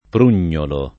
vai all'elenco alfabetico delle voci ingrandisci il carattere 100% rimpicciolisci il carattere stampa invia tramite posta elettronica codividi su Facebook prugnolo [ pr 2 n’n’olo ; ant. o region. prun’n’ 0 lo ] s. m. («susino selvatico»)